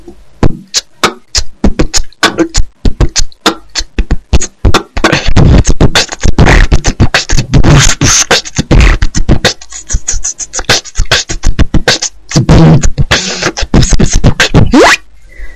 Выкладываем видео / аудио с битбоксом
b t k t bb t k t
bb t k t bb b t b k b kk
ничего толком не понятно( скрежет режет слух!(